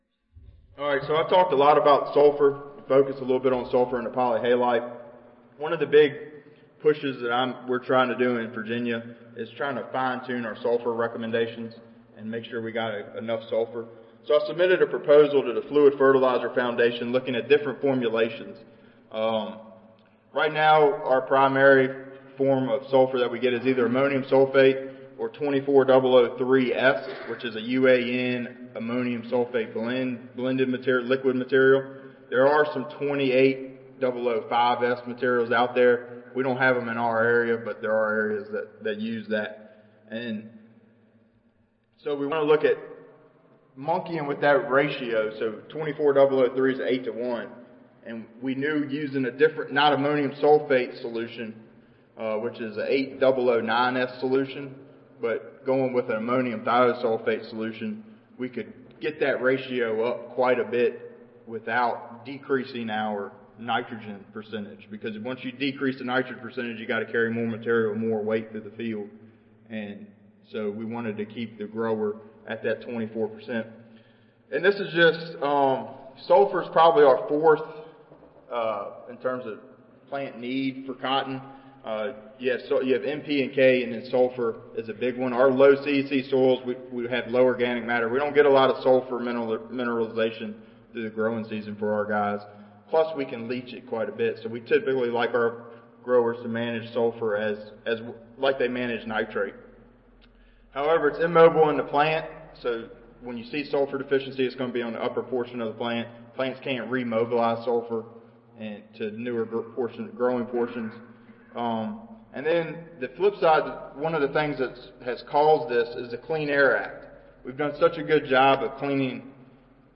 Reunion B (Hyatt Regency Dallas)
Recorded Presentation